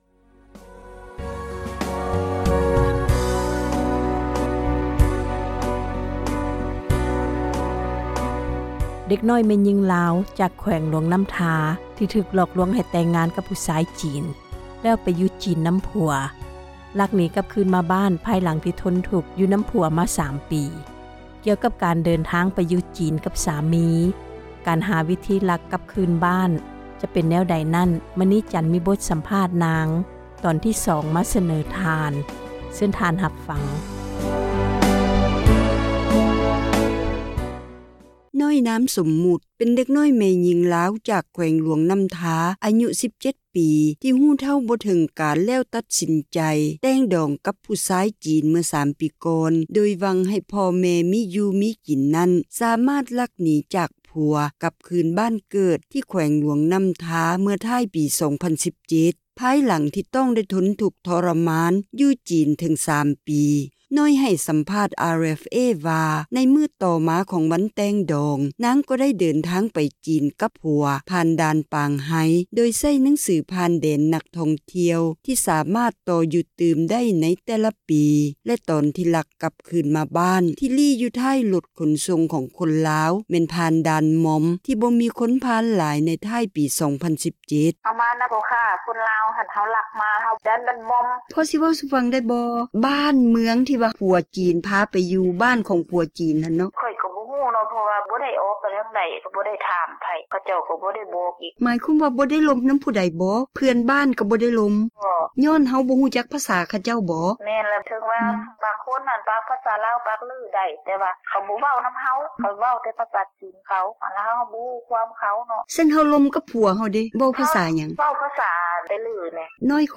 ສາວລາວ ລັກໜີ ຈາກສາມີ ຈີນ: ສັມພາດ (ຕໍ່)